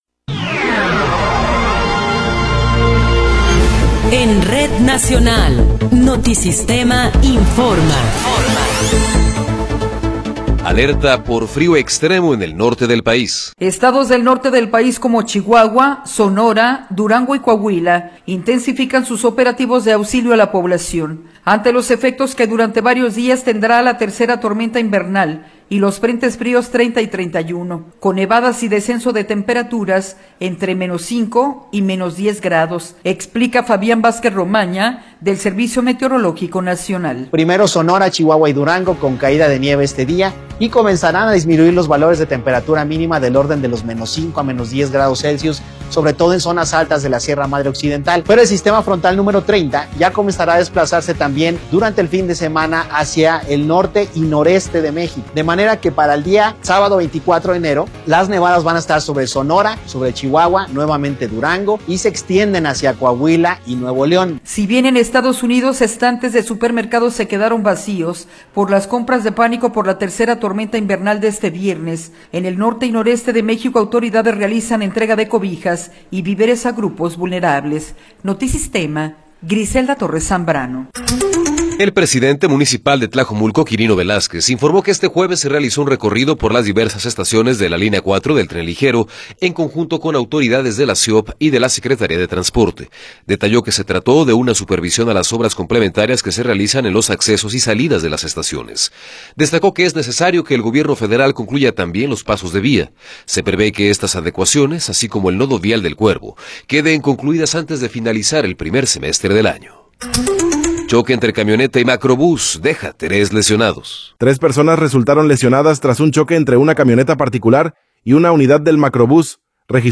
Noticiero 11 hrs. – 23 de Enero de 2026
Resumen informativo Notisistema, la mejor y más completa información cada hora en la hora.